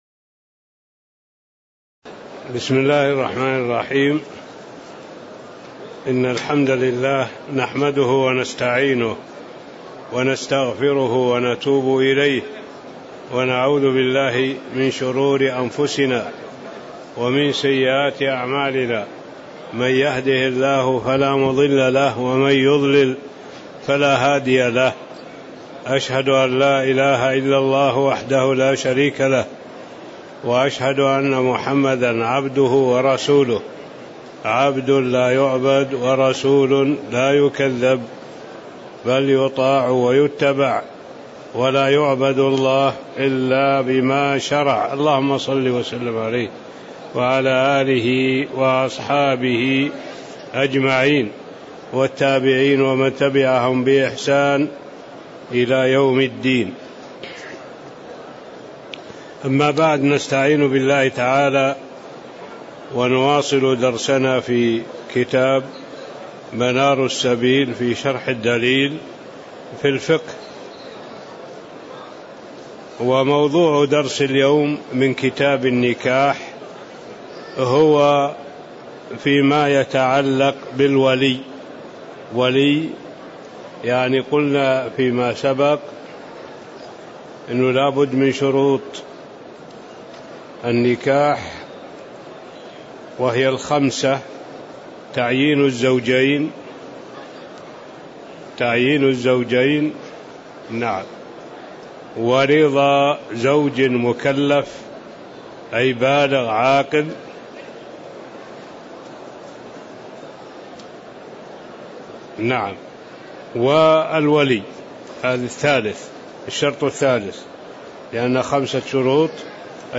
تاريخ النشر ١٢ جمادى الآخرة ١٤٣٧ هـ المكان: المسجد النبوي الشيخ